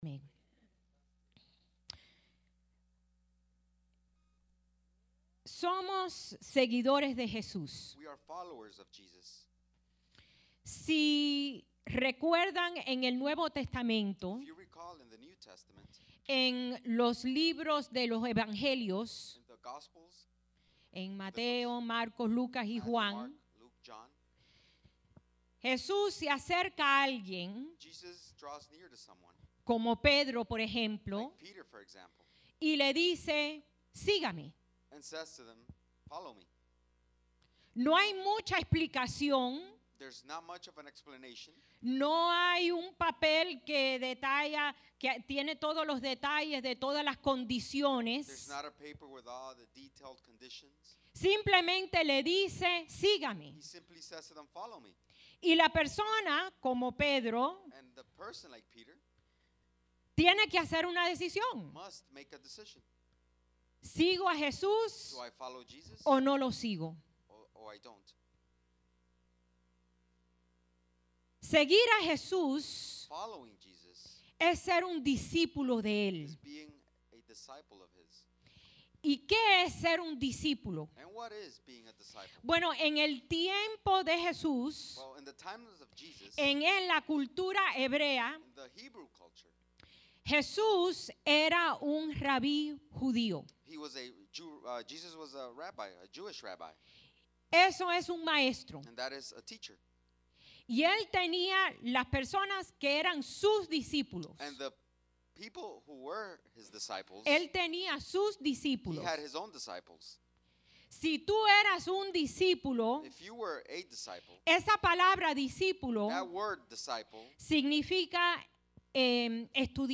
Sermons | Iglesia Centro Evangelico